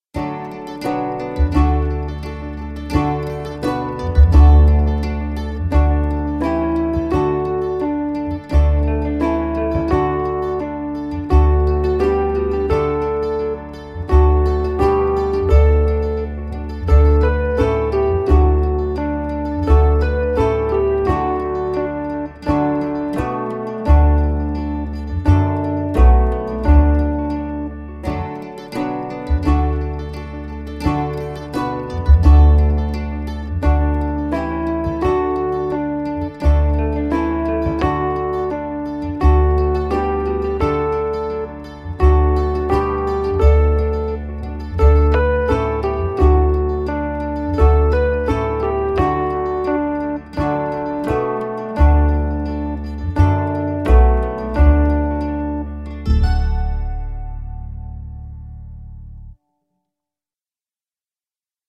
set to familiar tunes
Instrumental